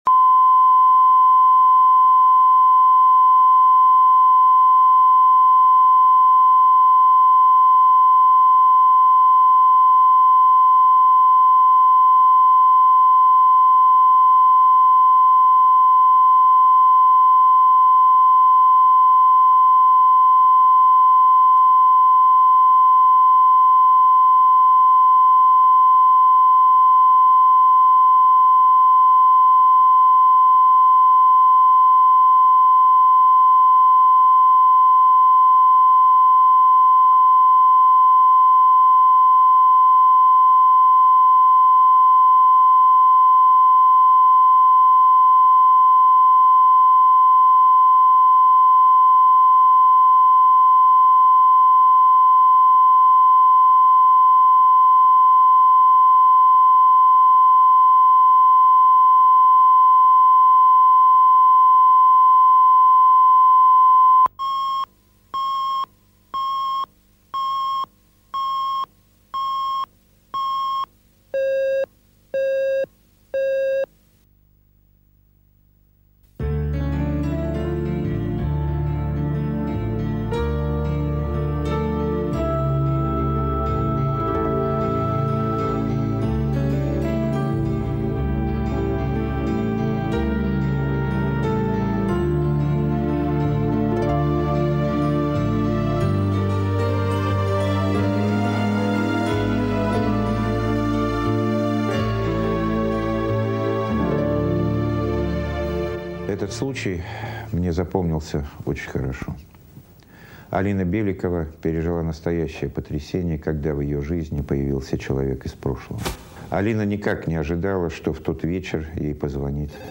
Aудиокнига Из прошлого Автор Александр Левин. Прослушать и бесплатно скачать фрагмент аудиокниги